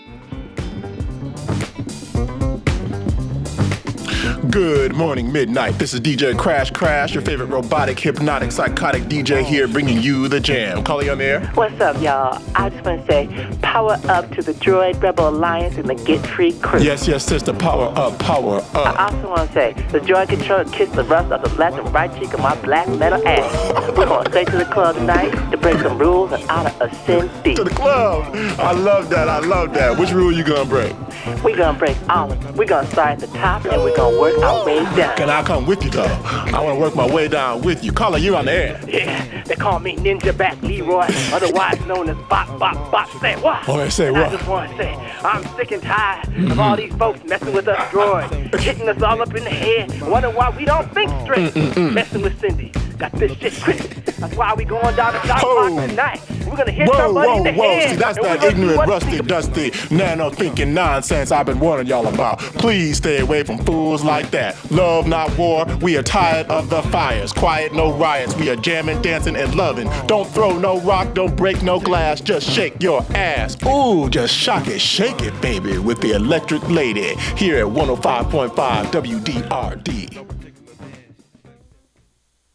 radio interludes